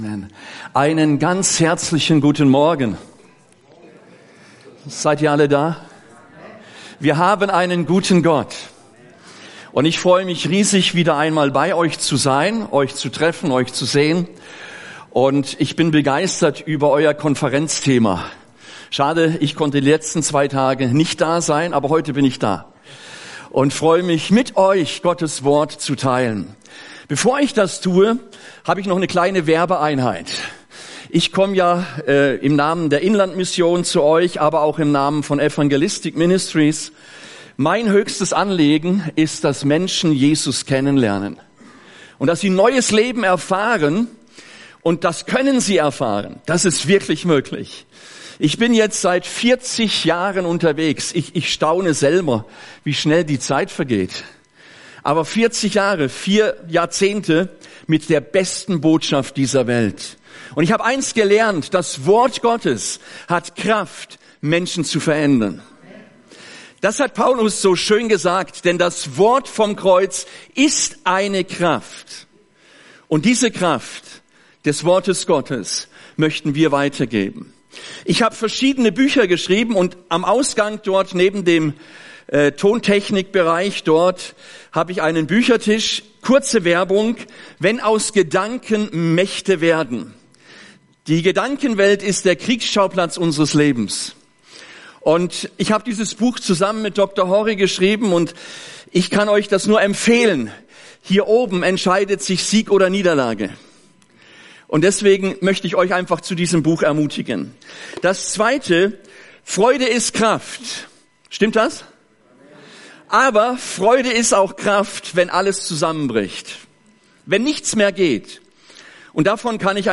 Die BE-Konferenz im CGA.